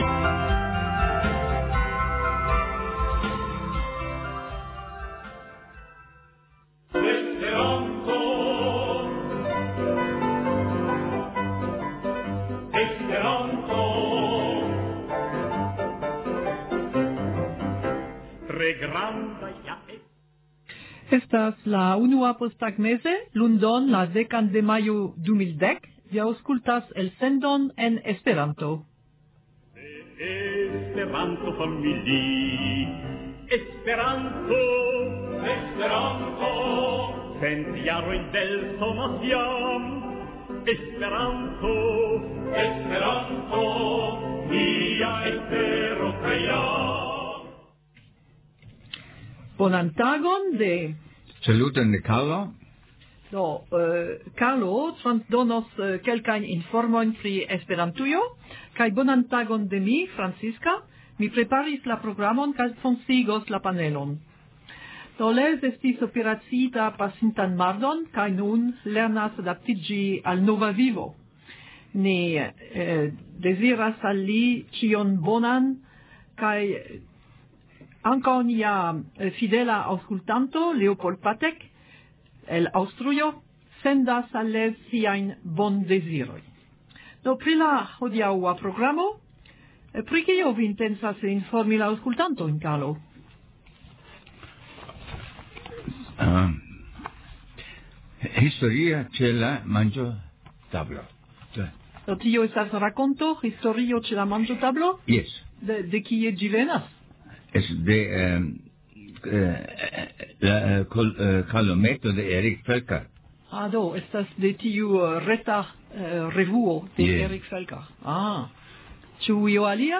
Legado
Intervjuo
Kanto
popolan kanton de Jugoslavio